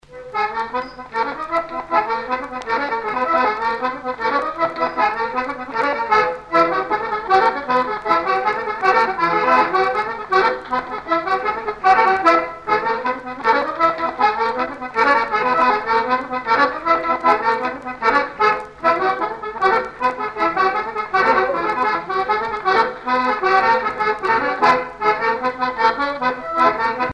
Pouillé-les-Côteaux
danse : branle : avant-deux
Pièce musicale éditée